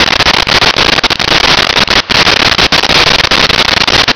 Sfx Amb Rain Loop
sfx_amb_rain_loop.wav